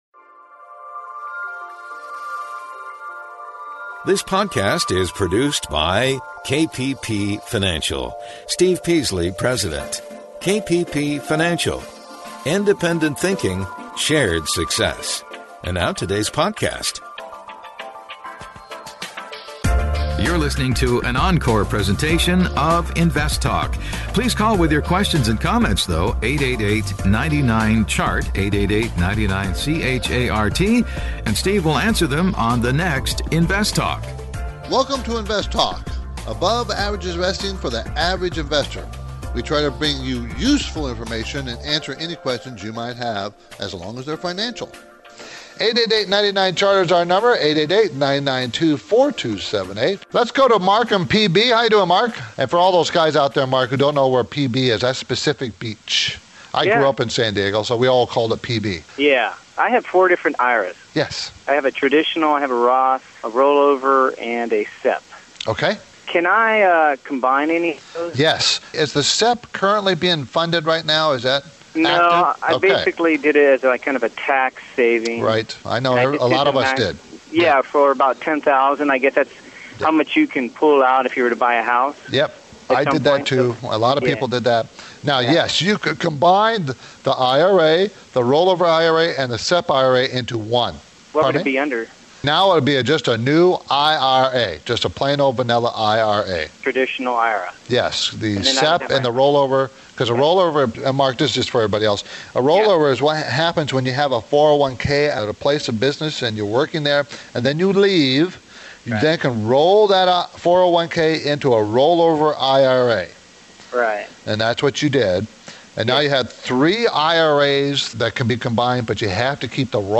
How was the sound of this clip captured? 04-08-20: Some of The Best InvestTalk Calls 02